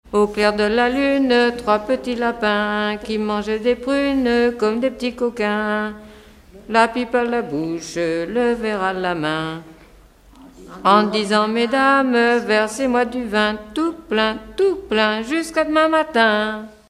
L'enfance - Enfantines - rondes et jeux
Pièce musicale éditée